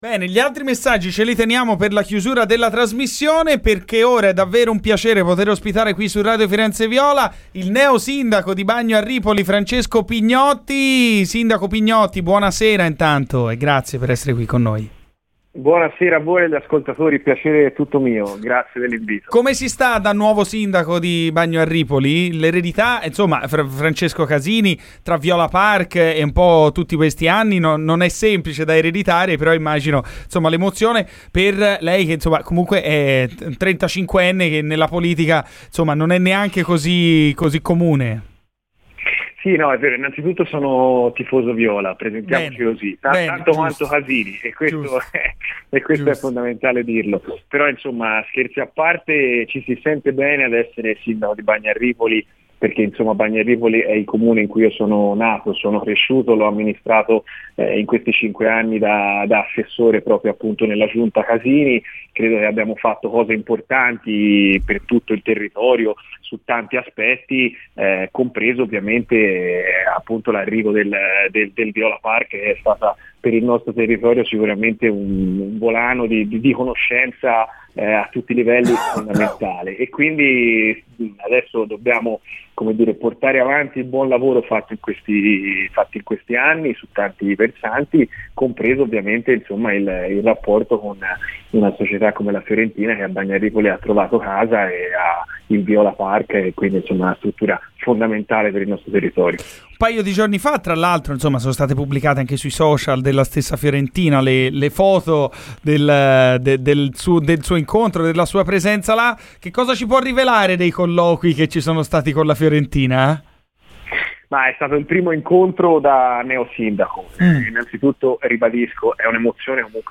Il neosindaco di Bagno a Ripoli Francesco Pignotti, ha parlato ai microfoni di Radio FirenzeViola, durante "Garrisca al Vento": "Il nostro obiettivo è quello di continuare a mantenere un ottimo rapporto con il club, così come fatto dall'ex sindaco Francesco Casini.